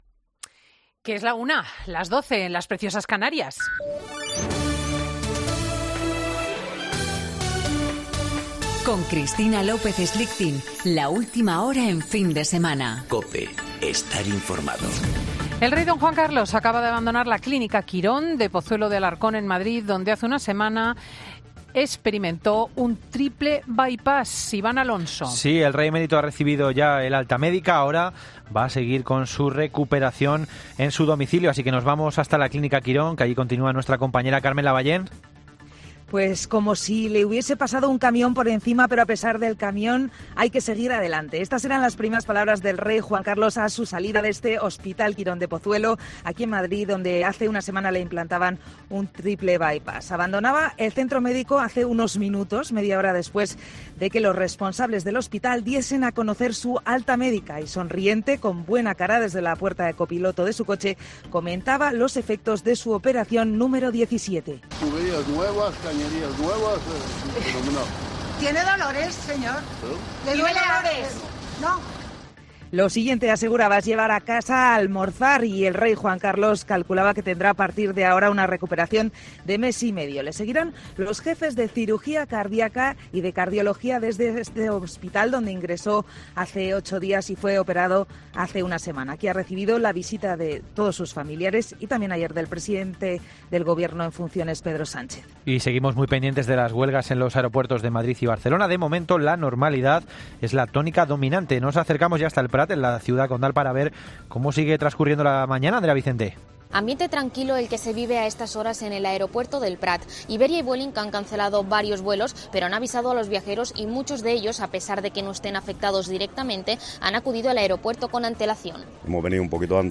Boletín de noticias de COPE del 31 de agosto de 2019 a las 13.00 horas